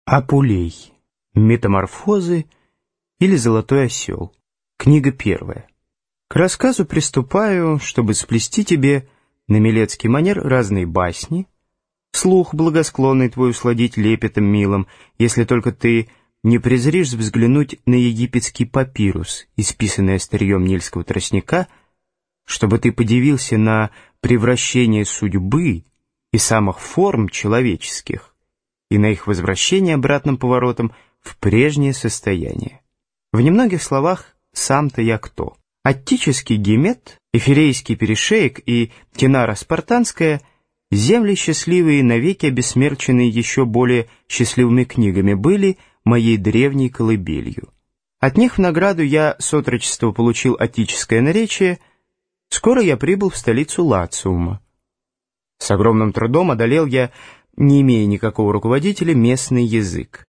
Аудиокнига Метаморфозы, или Золотой осел | Библиотека аудиокниг